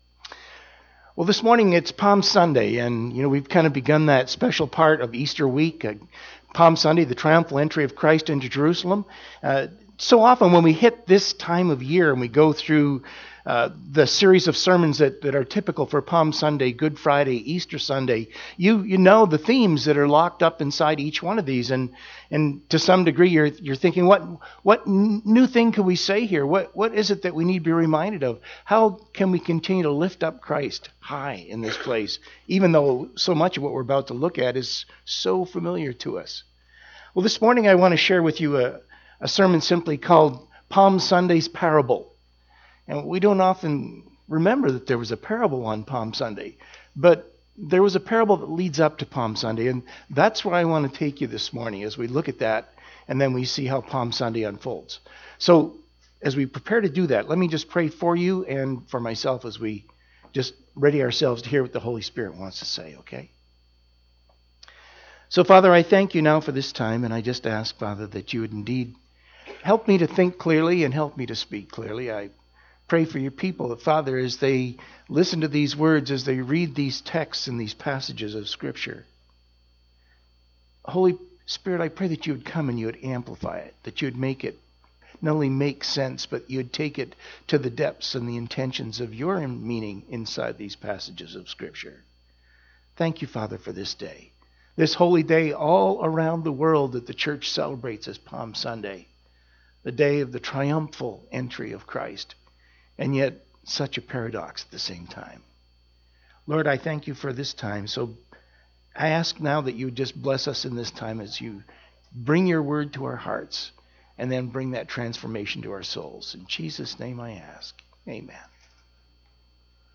Palm Sunday Parable | Sermons | Resources